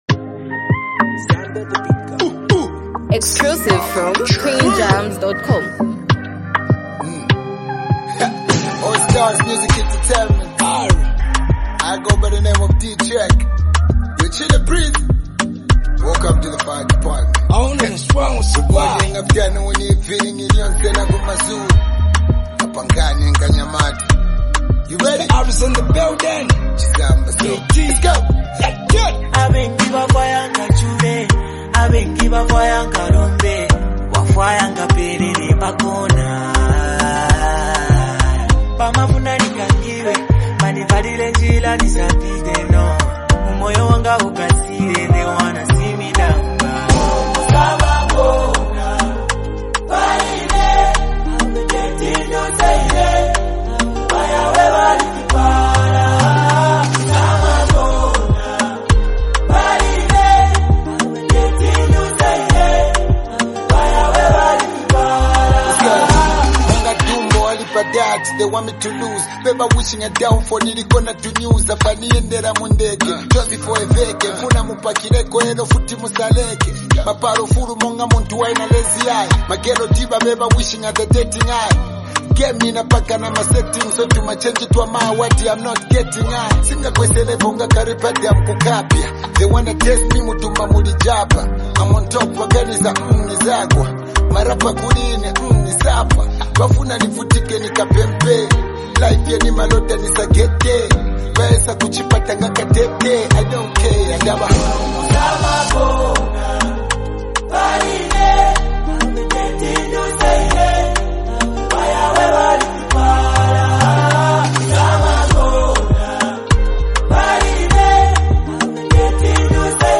Supported by a gentle yet powerful instrumental